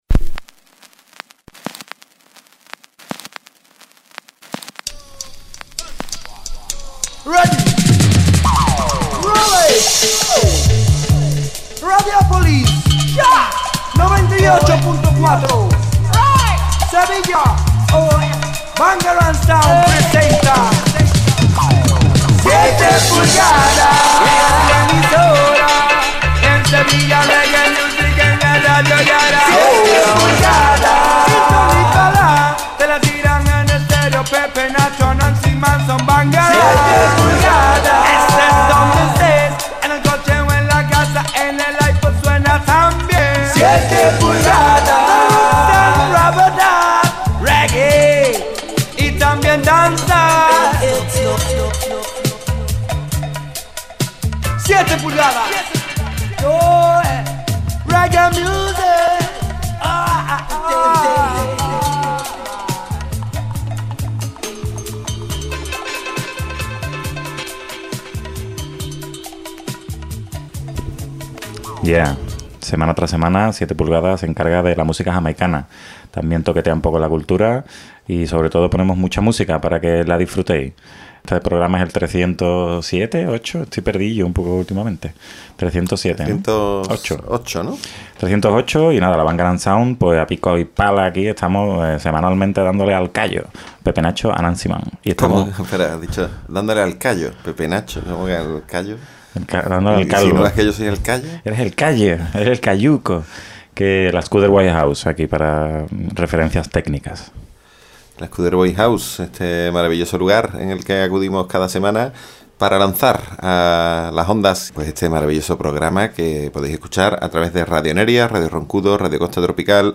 Presentado y dirigido por la Bangarang Sound y grabado en la Skuderbwoy House.